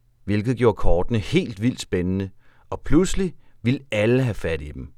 Updated intro voiceover
IntroVO3.wav